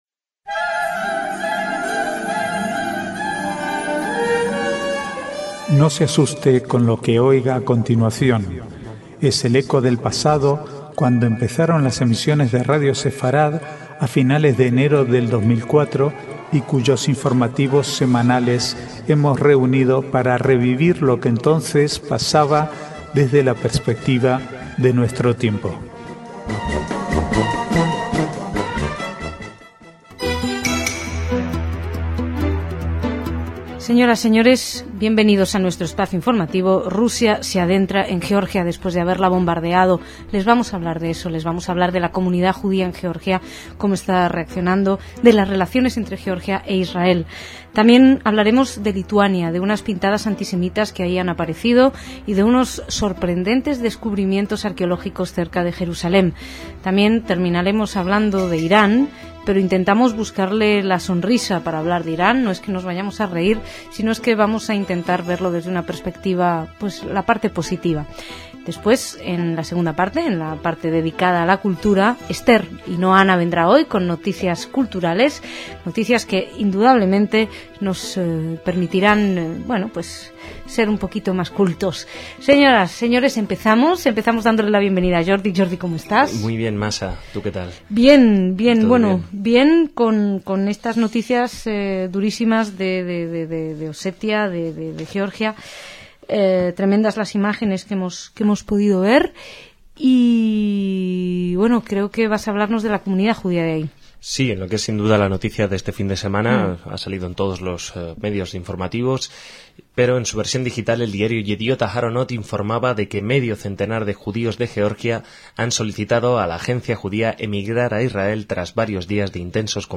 Archivo de noticias del 12 al 14/8/2008